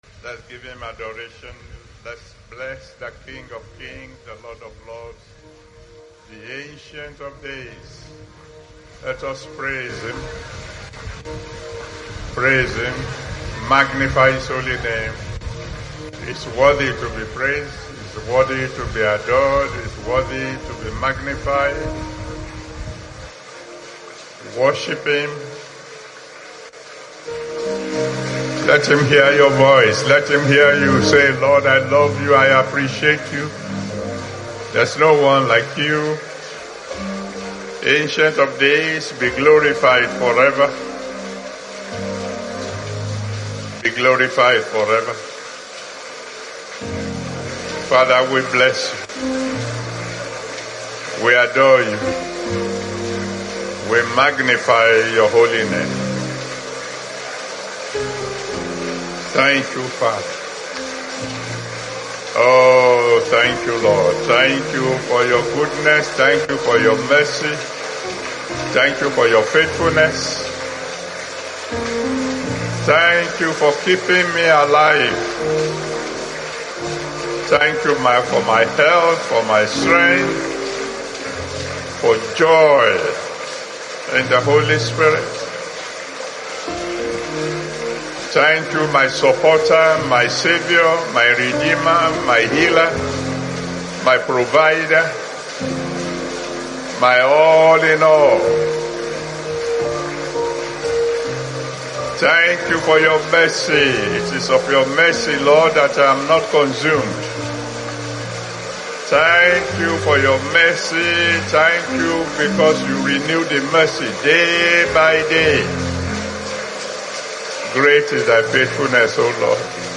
Flood of Abundance - Pastor E.A Adeboye [Sept. 2021 Thanksgiving Service]